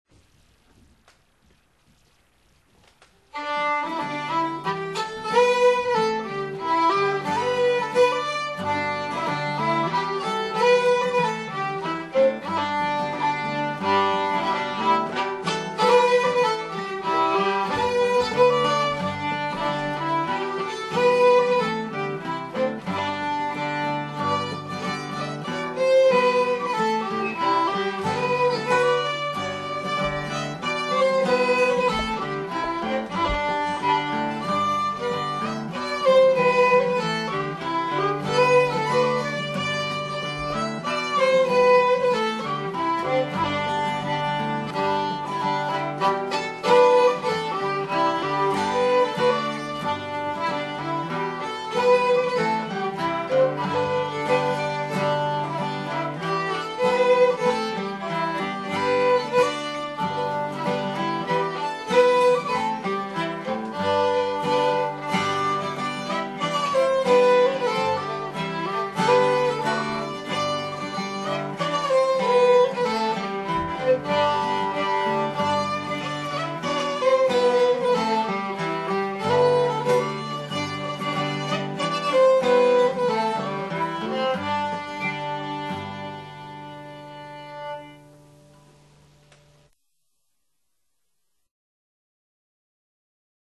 Recorded at Flying Fiddle Studio
Mandolin
Octave Mandolin
Guitar